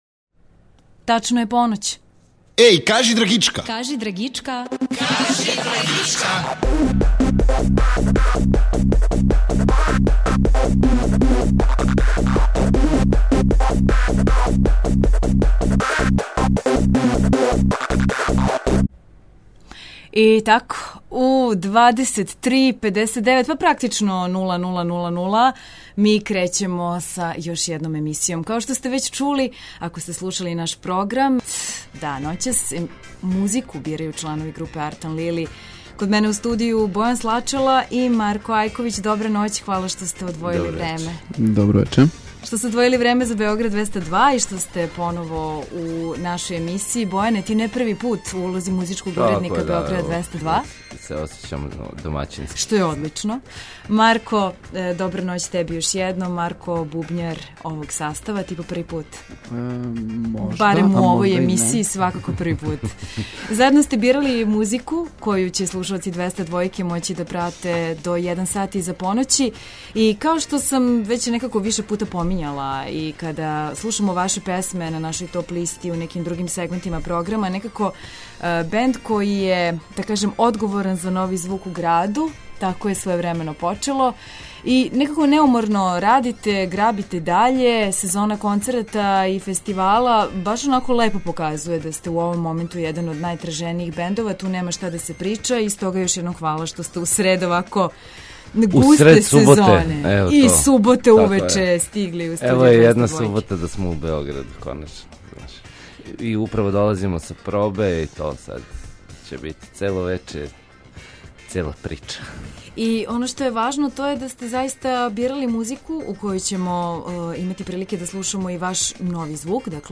Ноћас музику бирају чланови групе "Артан Лили".